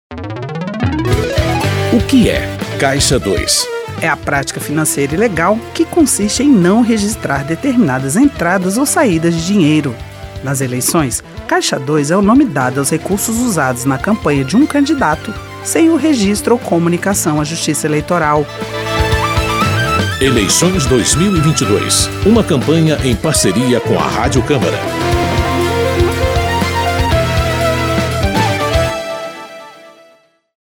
São 13 spots de 30 segundos.